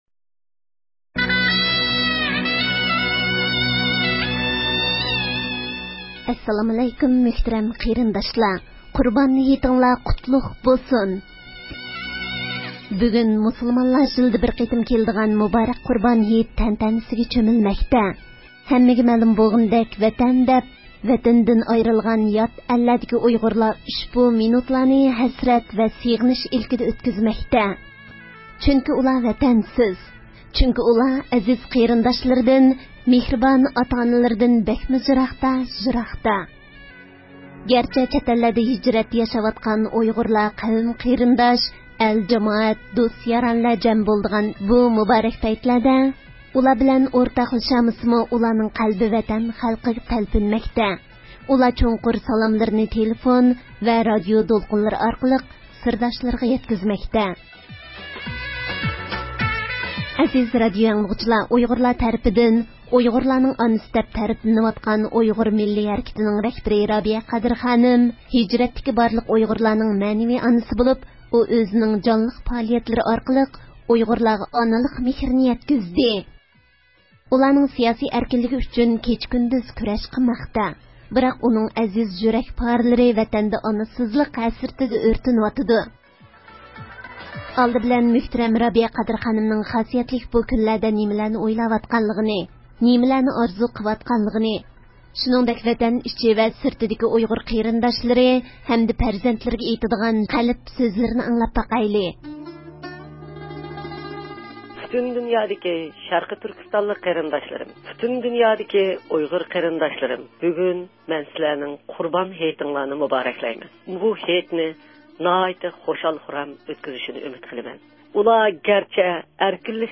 ئۇيغۇر مىللىي ھەرىكىتىنىڭ رەھبىرى رابىيە قادىر خانىم باشلىق بىر قىسىم ئۇيغۇر مۆتىۋەرلىرى ھېيتلىق سالاملىرىنى رادىئومىز ئارقىلىق ۋەتەن ئىچى  – سىرتىدا ياشاۋاتقان ئۇيغۇرلارغا يوللىدى.